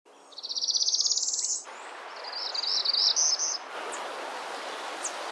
所有的录音都是在威斯康星州录制的，尽管*表示该物种不在威斯康星州繁殖。
北部Parula
WARBLER__PARULA.MP3